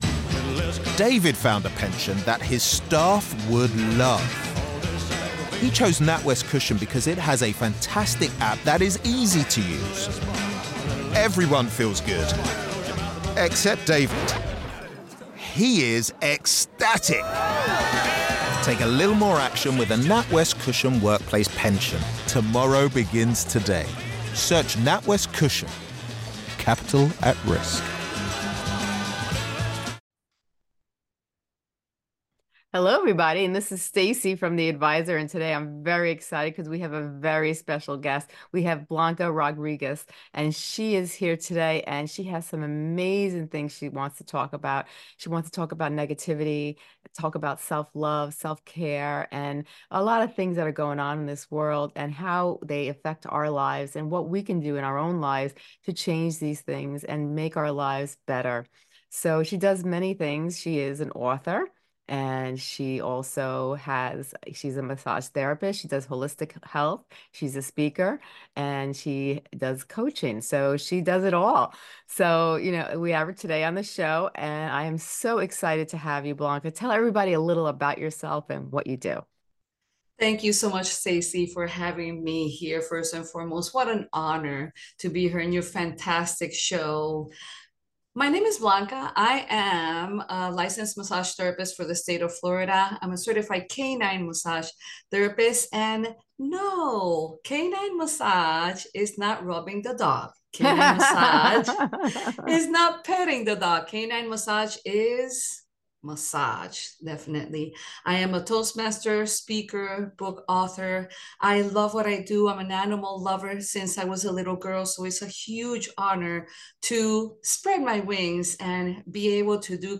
The podcast featured a conversation between two speakers discussing the importance of self-love, self-care, and therapy in improving one's life.